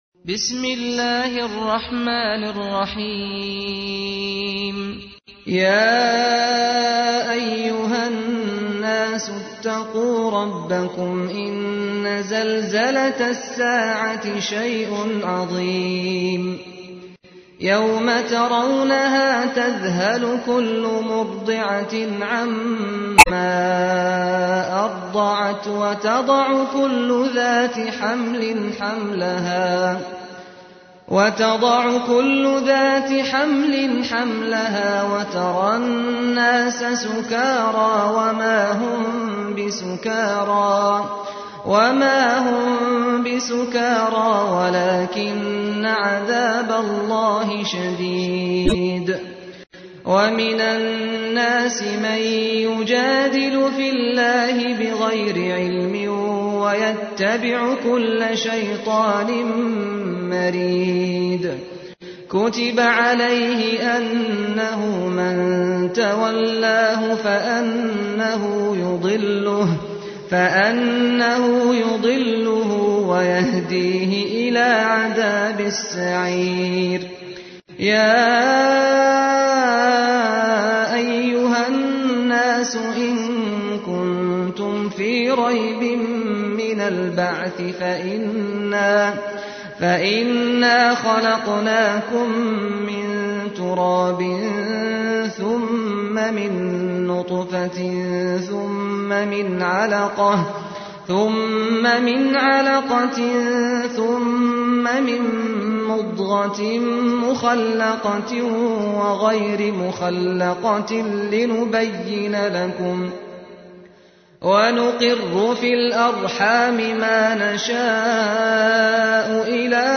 تحميل : 22. سورة الحج / القارئ سعد الغامدي / القرآن الكريم / موقع يا حسين